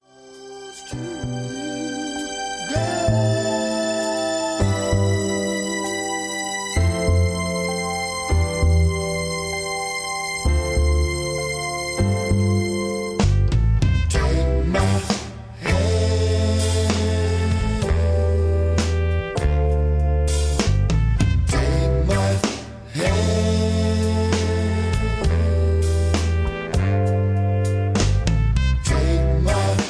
Karaoke MP3 Backing Tracks
Just Plain & Simply GREAT MUSIC (No Lyrics).